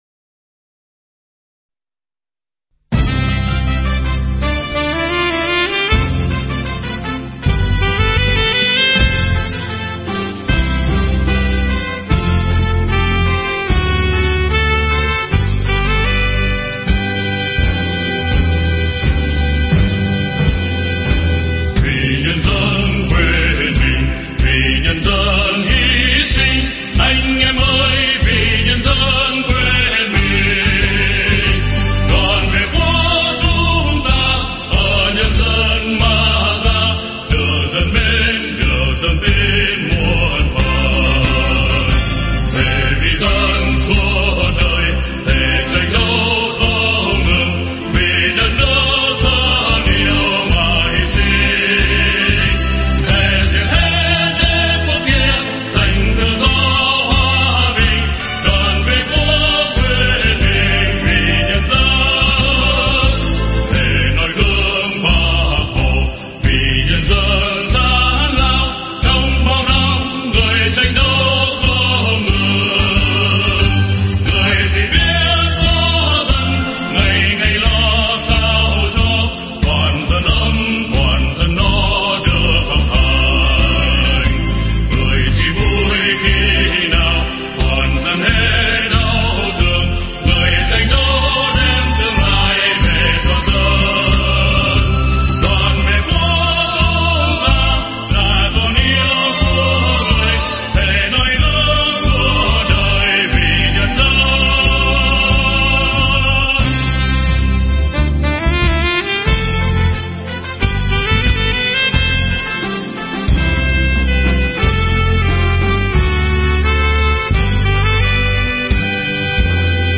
File nhạc có lời